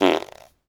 fart_squirt_05.wav